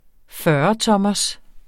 Udtale [ ˈfɶːʌˌtʌmʌs ]